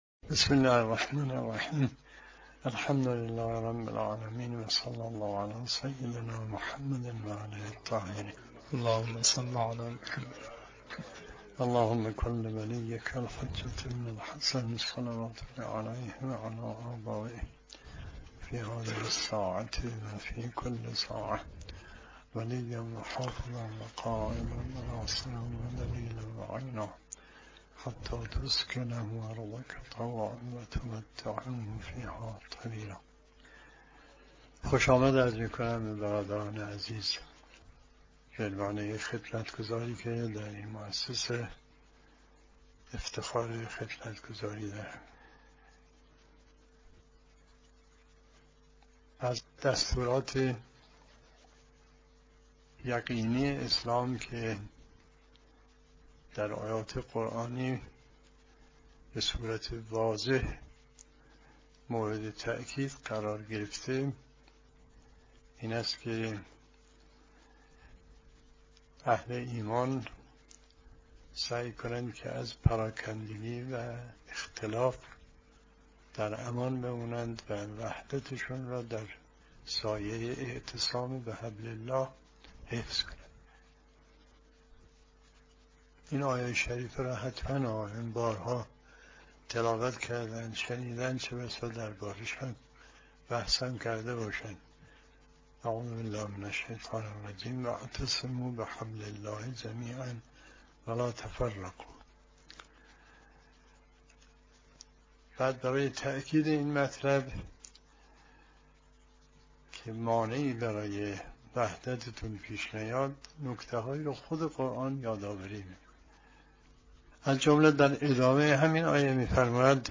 بیانات حضرت آیت‌ الله مصباح (ره) در دیدار با جمعي از شيعيان کشور ترکيه